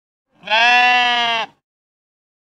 sheep-bleet.ogg.mp3